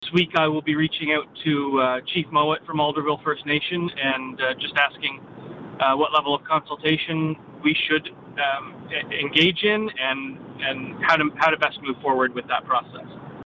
Mayor of Brighton Brian Ostrander.